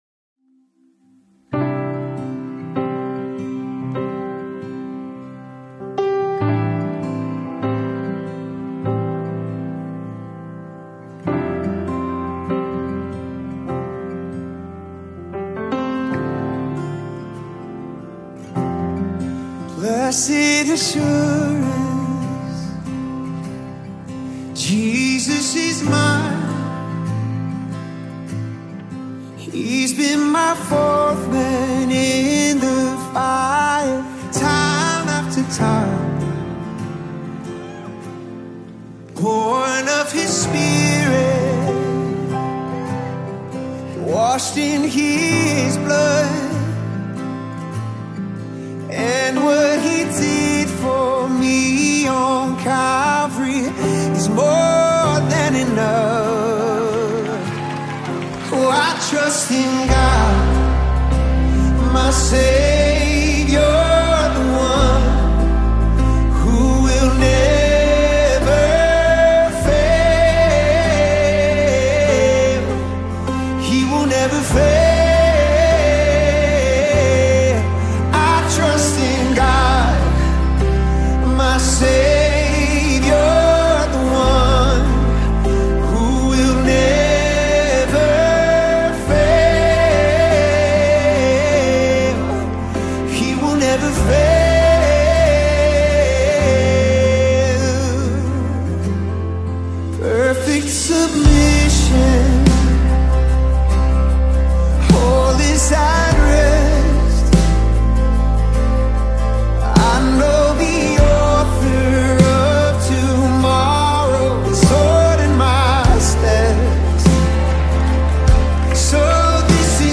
American Gospel Songs